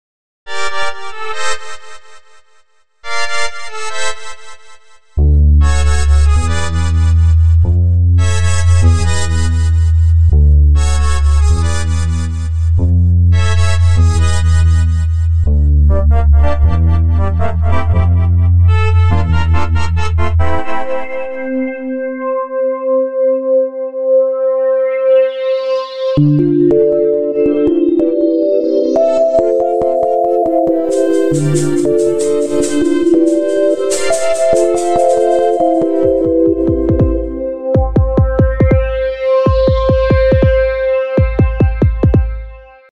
Interesting, progressive and calm music.